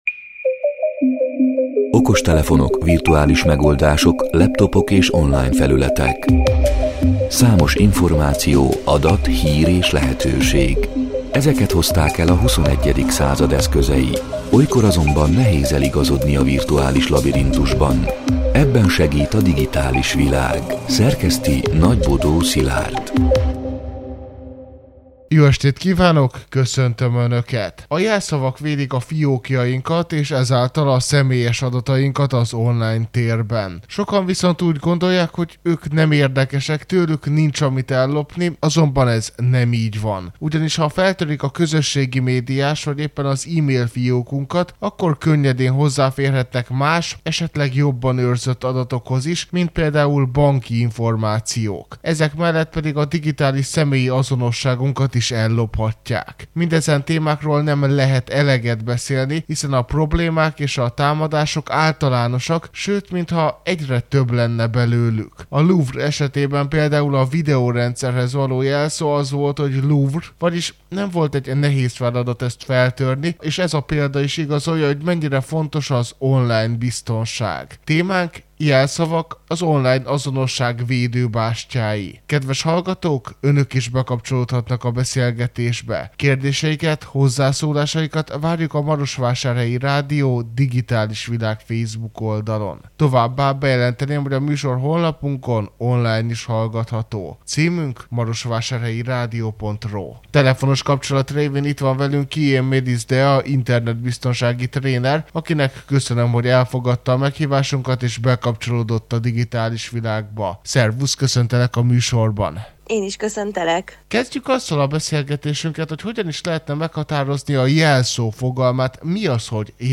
A Marosvásárhelyi Rádió Digitális Világ (elhangzott: 2025. november 11-én, kedden este nyolc órától) c. műsorának hanganyaga: